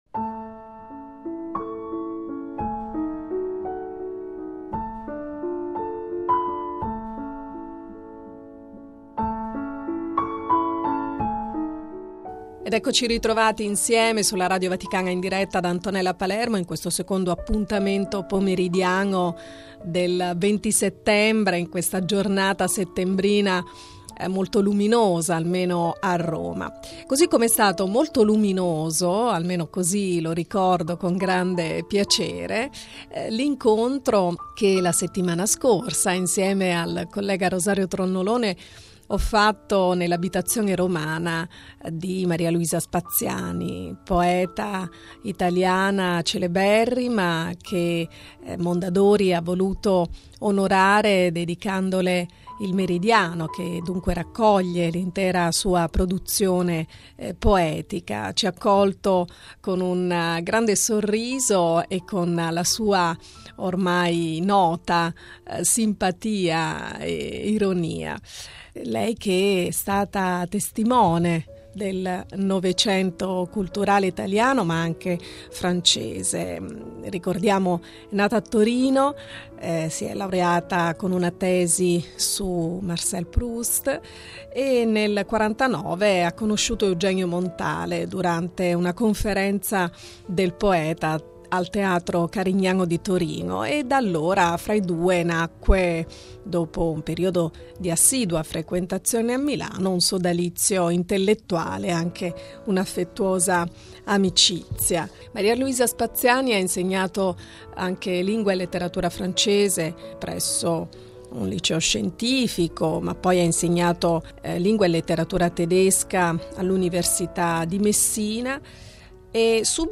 A casa del poeta Maria Luisa Spaziani.